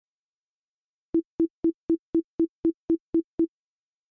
Das, was in "Tonfolge 2" auf dem rechten Lautsprecher zu h�ren ist, ist genau so und auch in dieser Lautst�rke bereits in "Tonfolge 1" auf dem rechten Lautsprecher zu h�ren.
Der "Trick" hinter den dargebrachten T�nen ist folgender: linker und rechter Lautsprecher geben tats�chlich dieselben T�ne in identischer Lautst�rke wieder, aber die T�ne auf dem rechten Lautsprecher kommen 5 Millisekunden sp�ter.